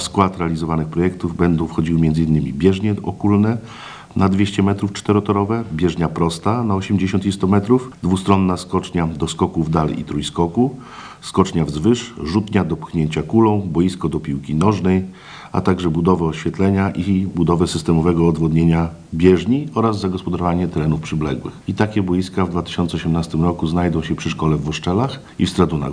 O tym, że inwestycje zostaną zrealizowane w przyszłym roku na konferencji prasowej poinformowali we wtorek (3.10) wójt gminy Ełk Tomasz Osewski i senator Małgorzata Kopiczko.
– Mieszkańcy gminy, szczególnie ci najmłodsi będą mogli rozwijać swoje sportowe pasje w komfortowych warunkach – mówi wójt Osewski.